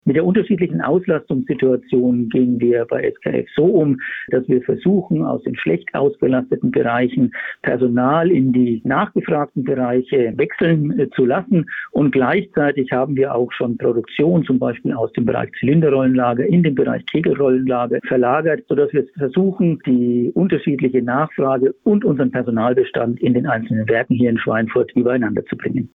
Interview: Großer Stellenabbau bei SKF in Schweinfurt - PRIMATON